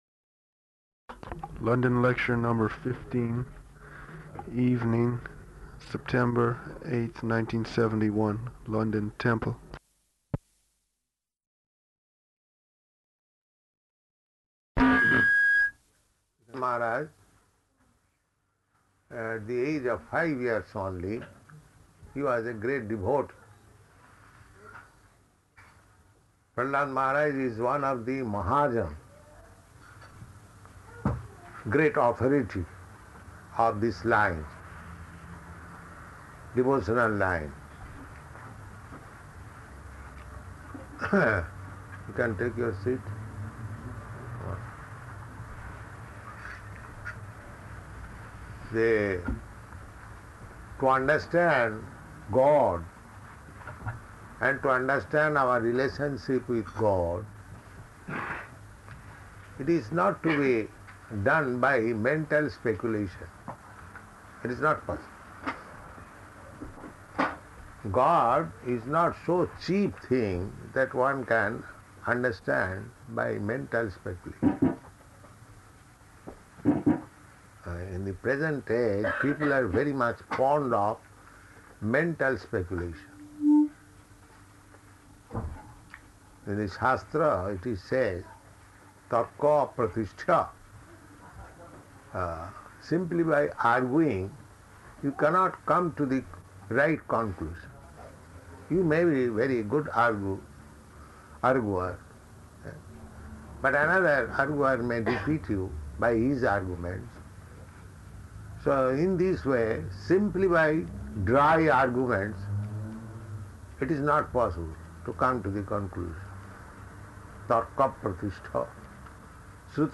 Śrīmad-Bhāgavatam 7.5.22–30 --:-- --:-- Type: Srimad-Bhagavatam Dated: September 8th 1971 Location: London Audio file: 710908SB-LONDON.mp3 Devotee: [introducing recording] London lecture number 15, evening, September 8th, 1971, London temple.